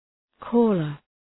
Προφορά
{‘kælər}